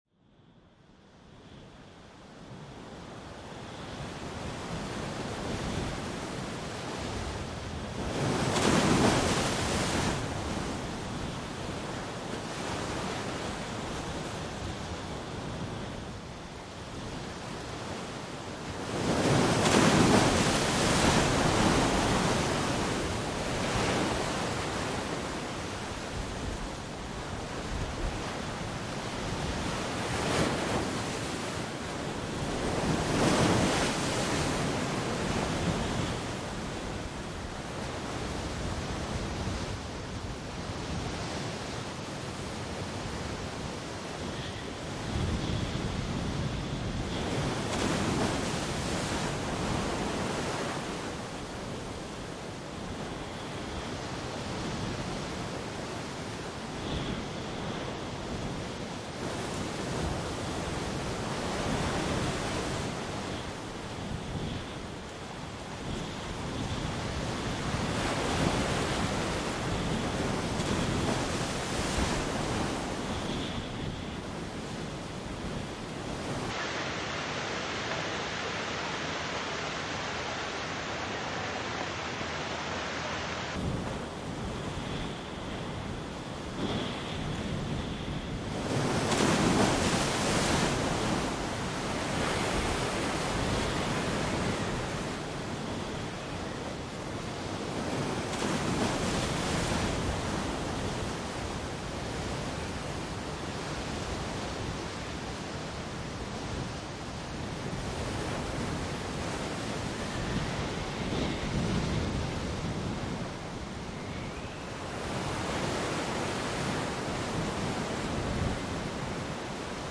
2. Det blåser upp & Storm över Östersjön (ljudfil)
2.Det-blåser-upp-&-Storm-över-Östersjön.mp3